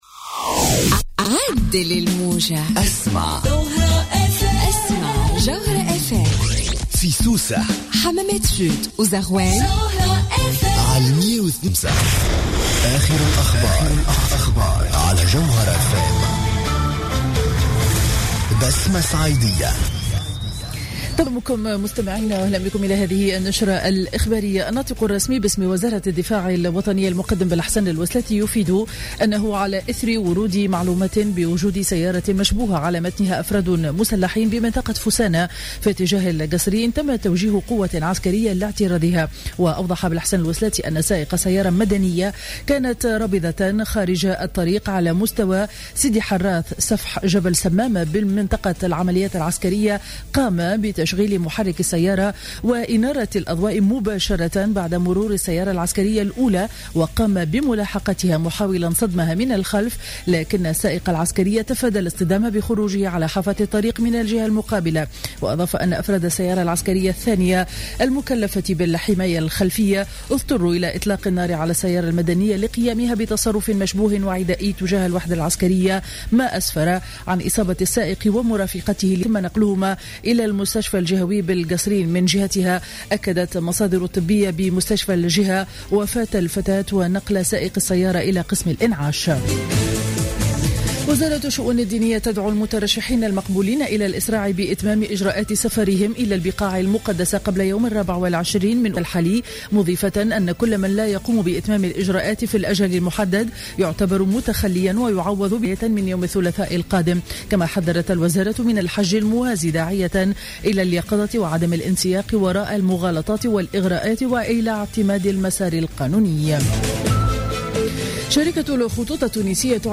نشرة أخبار السابعة صباحا ليوم الأحد 16 أوت 2015